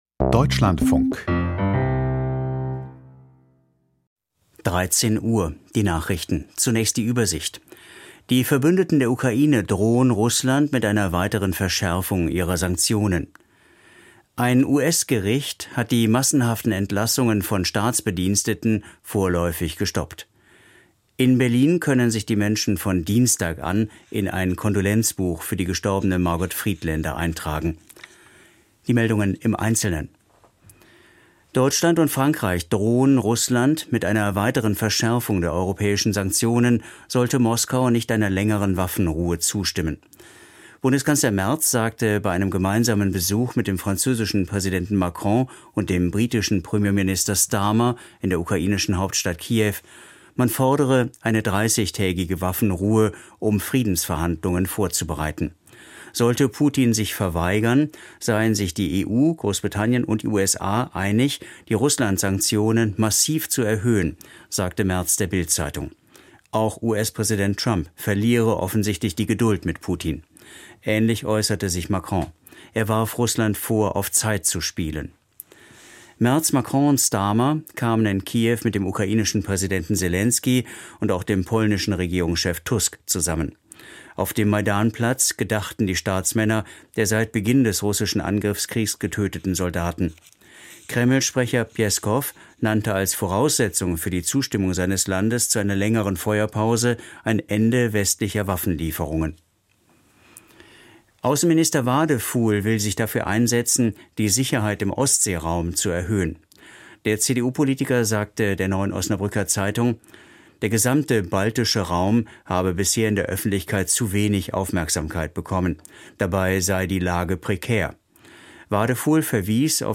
Die Nachrichten vom 10.05.2025, 13:00 Uhr